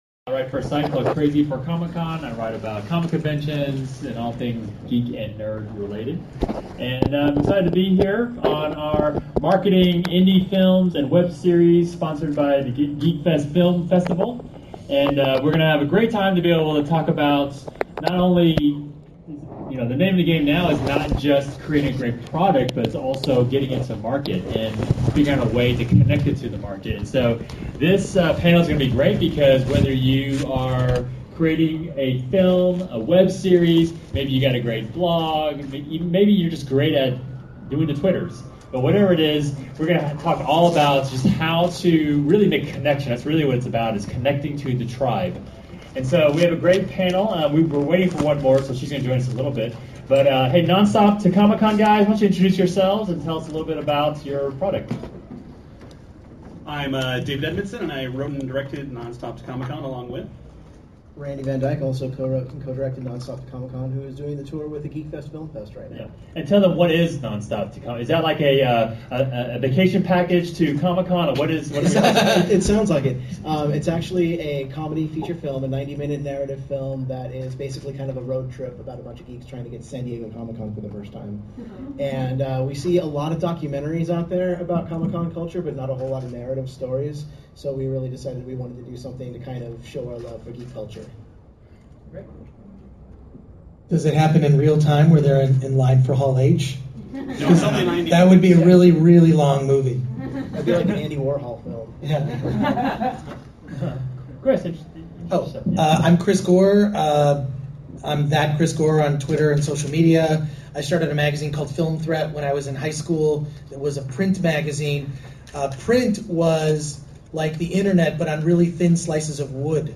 Marketing and Promoting Your Indie Film and Web Series Panel at Comikaze 2015
The full panel is below, adult language so parental discretion is advised.